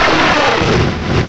cry_not_magmortar.aif